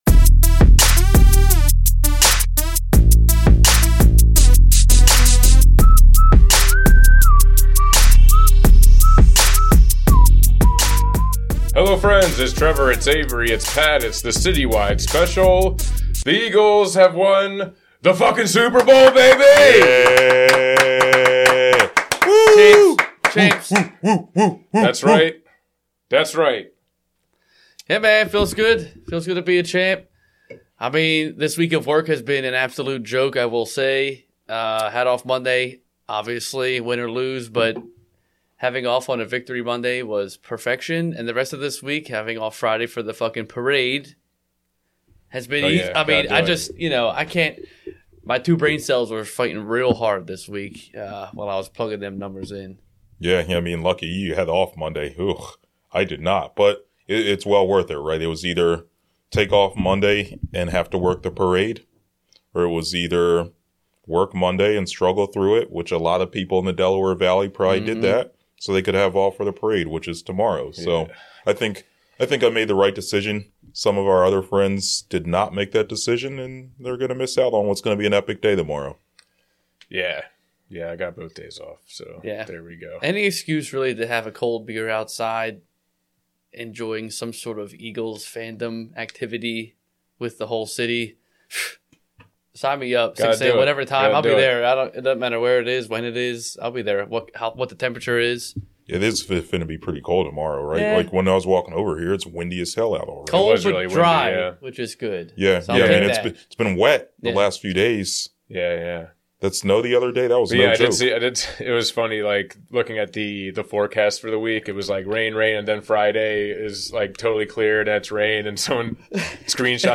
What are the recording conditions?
Due to some technical difficulties the last 12 or so minutes has crappy audio.